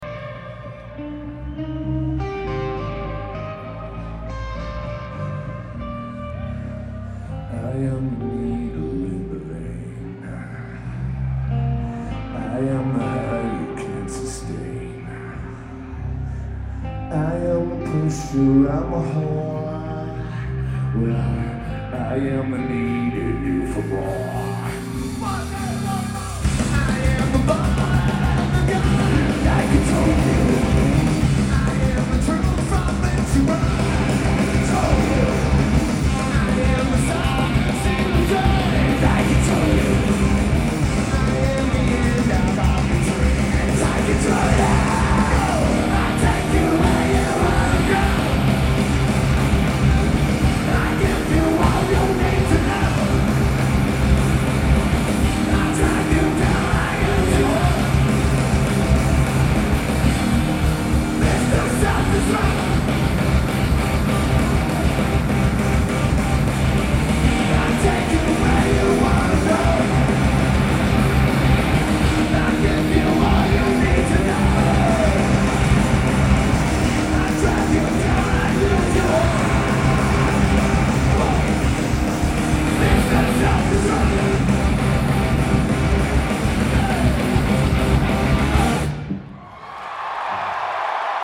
Journal Pavillion